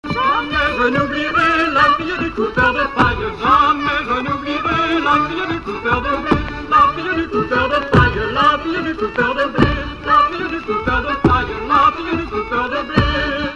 Courante
danse : branle : courante, maraîchine ;
Enquête Arexcpo en Vendée
Pièce musicale inédite